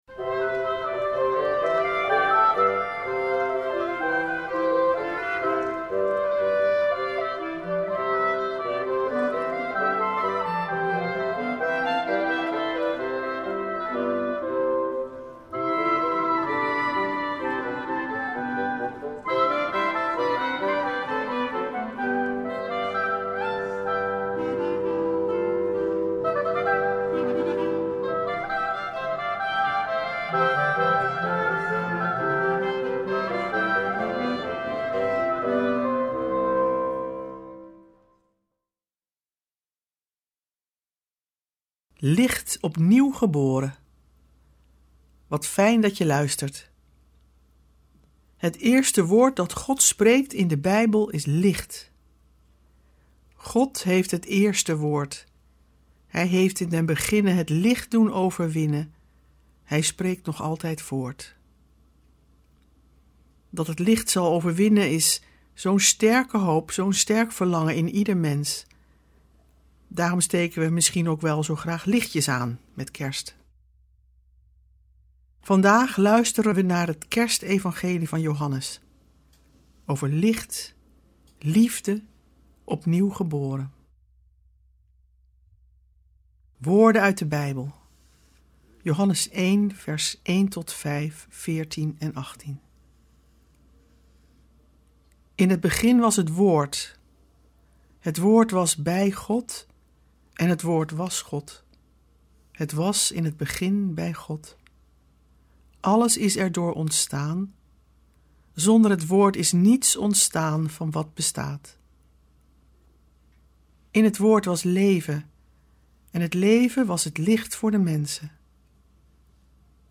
Met muziek en een actuele reflectie.